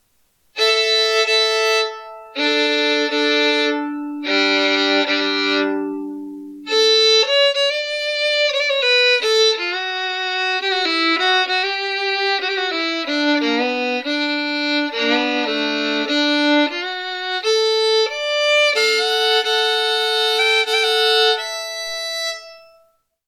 Vintage Grafted Carlo Micelli Violin/Fiddle $1275